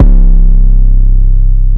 808 7 [ kick it in ].wav